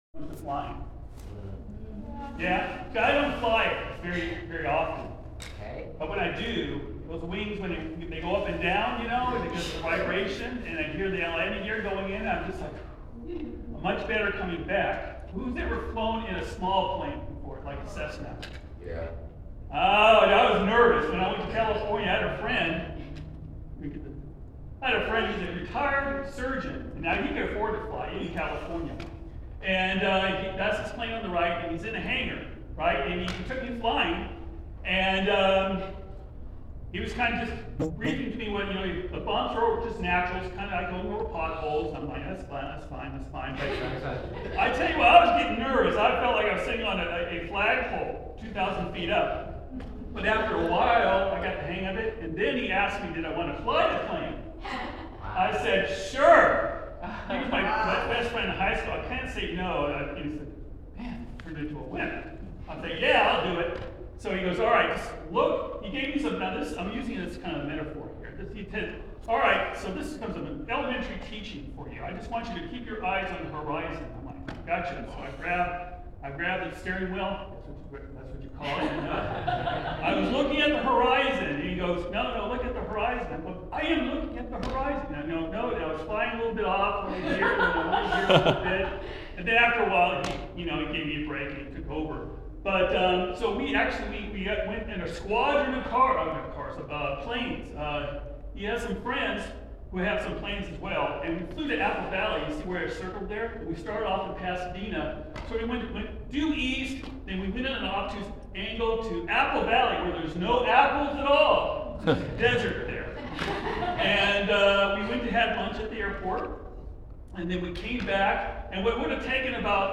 Sermons | Tri-County Church